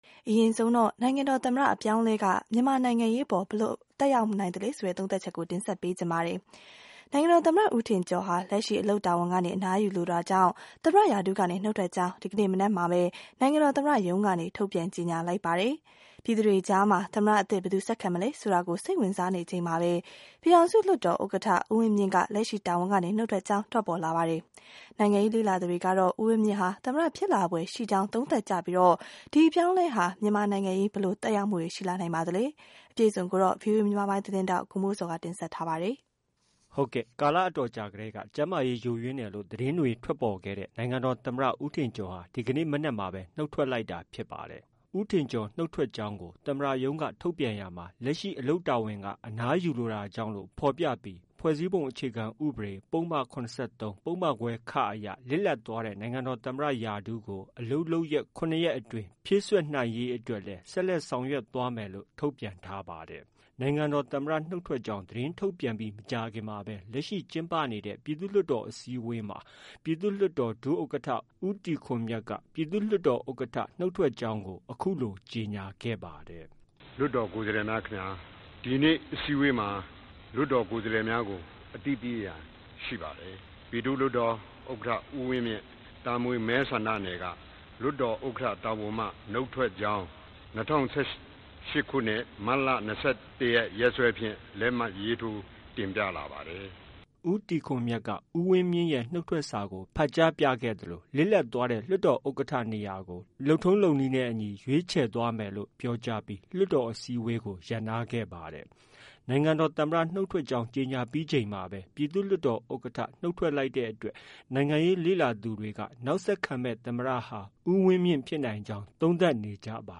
နိုင်ငံတော် သမ္မတနုတ်ထွက်ကြောင်း သတင်းထုတ်ပြန်ပြီး မကြာခင်မှာပဲ လက်ရှိ ကျင်းပနေတဲ့ ပြည်သူ့လွှတ်တော် အစည်းအဝေးမှာ ပြည်သူ့လွှတ်တော် ဒုဥက္ကဌ ဦးတီခွန်မြတ်က ဥက္ကဌ နုတ်ထွက်ကြောင်း အခုလို ကြေညာခဲ့ပါတယ်။